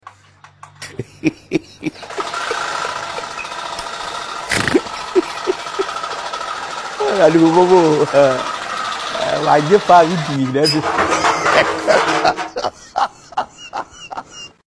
Aerofan Crash🤣🤣🤣 sound effects free download